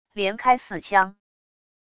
连开四枪wav音频